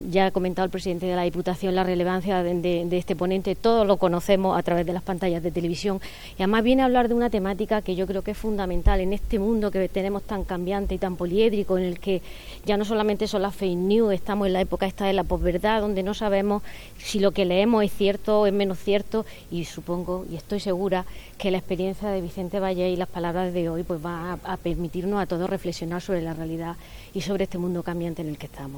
El periodista, presentador y director de Noticias 2 de Antena 3, ha participado en la clausura del Curso de Verano de la UAL sobre comunicación que se celebra en el Salón de Actos del MUREC